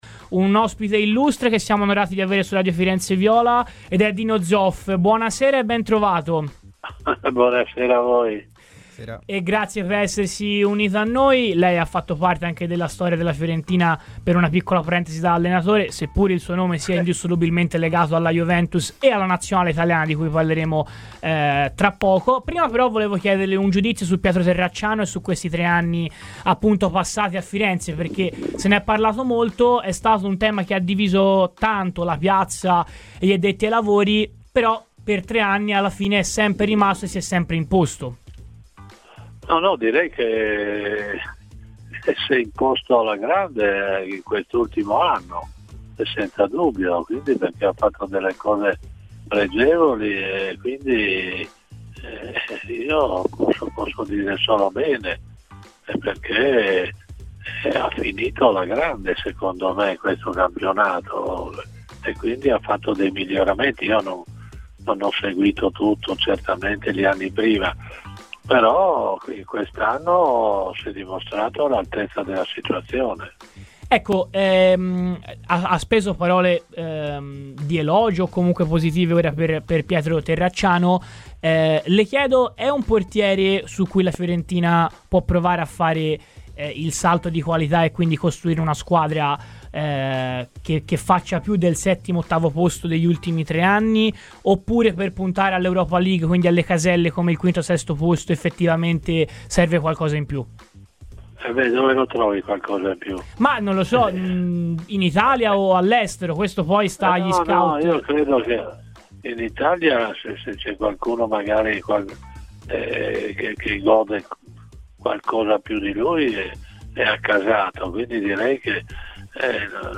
L'ex portiere della Juventus e allenatore della Fiorentina, Dino Zoff, è intervenuto oggi a Radio FirenzeViola durante la trasmissione "Viola Weekend" per parlare della porta viola, trovando spazio anche per esprimere la sua opinione sulla nazionale azzurra e sul percorso finora seguito da Spalletti all'Europeo.